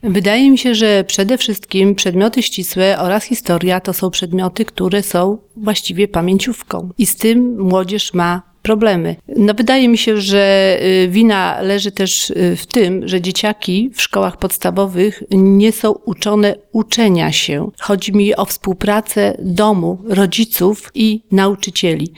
mówiła na naszej antenie